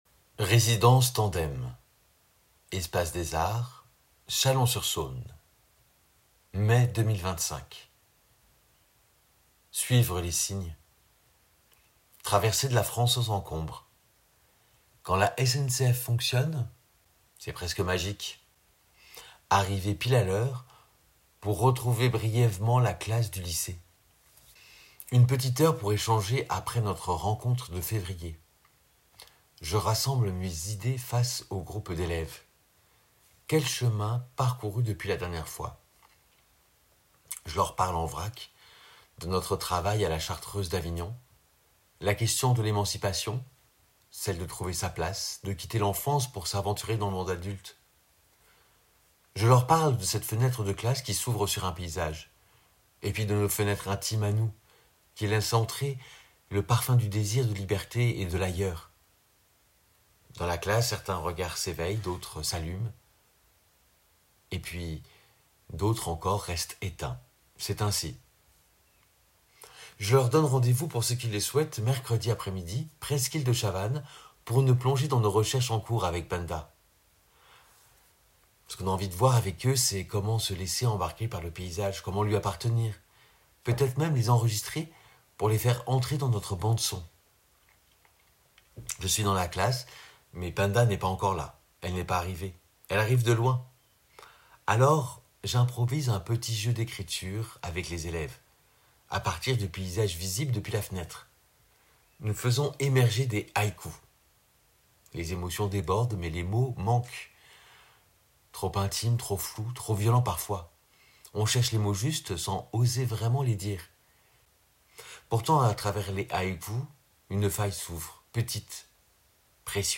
récit sonore